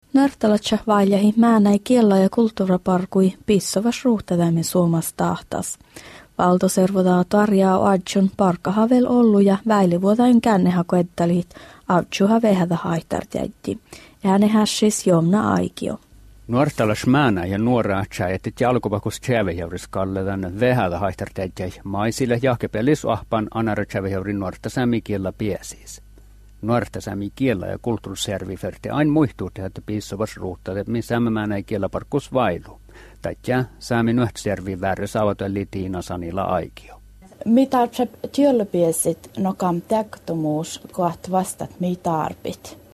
Although the last speaker seems to use the ǩ sound that is found in Skolt.
8 May 2011 at 11:20 am Definitely a saami language spoken by speakers whose pronunciation is influenced by finnish.